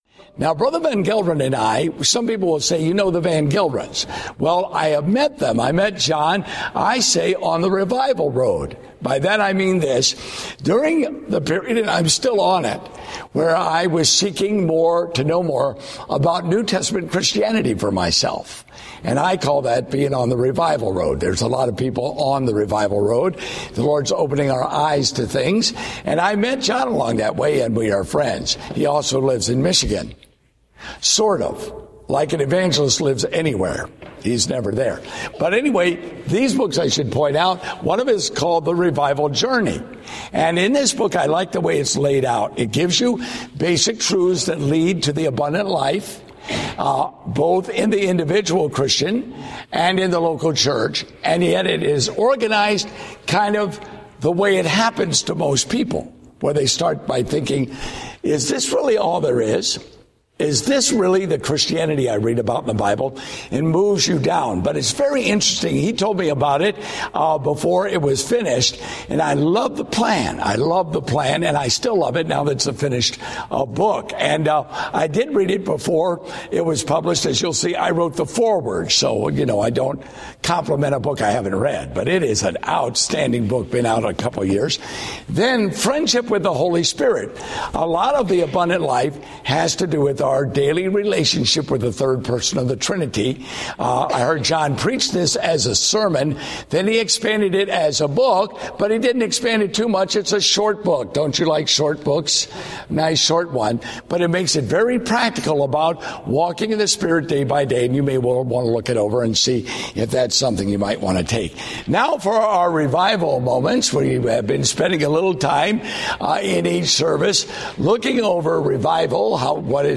Date: October 26, 2013 (Revival Meeting)